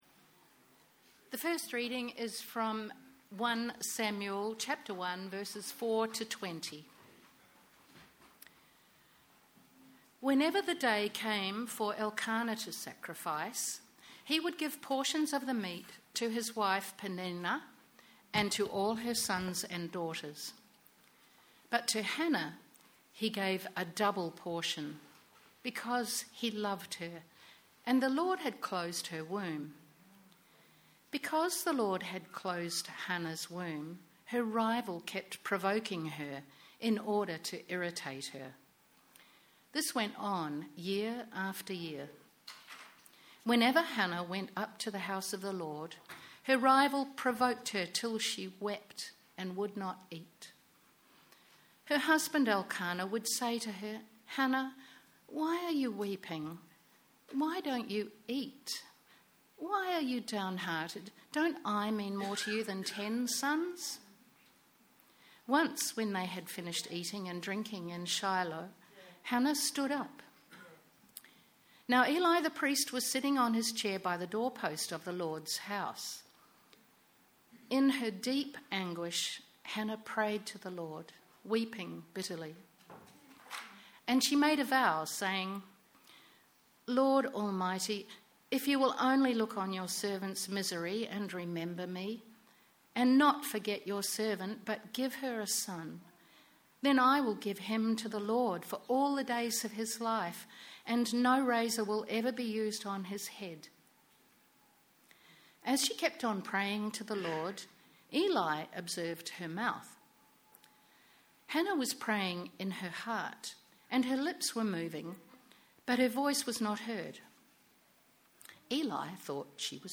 Sermons | Living Water Anglican Church
Bp Ian Coutts - Reception Service